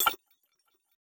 Futuristic Sounds (21).wav